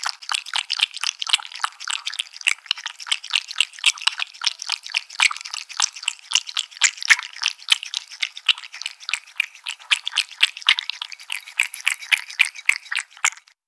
Звуки чавканья
Чемпион чавканья